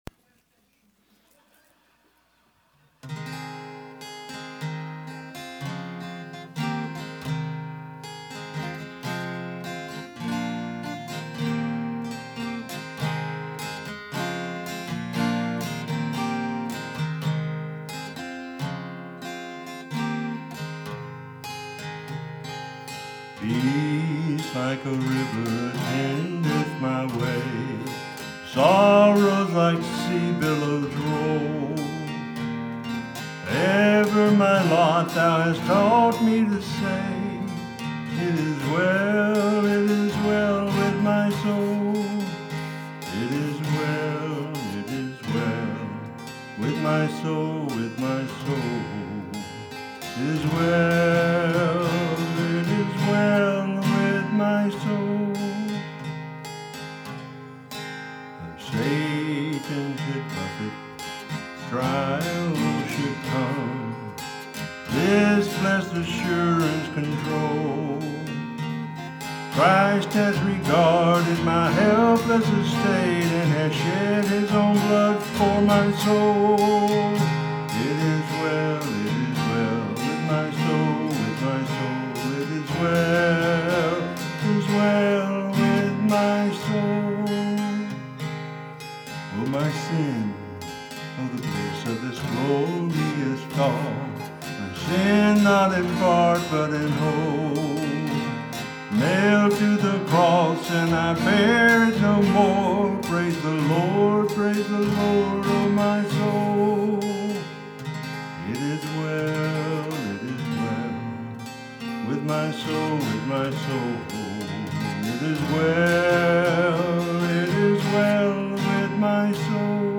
This is my husband singing and playing “It is well” on his guitar.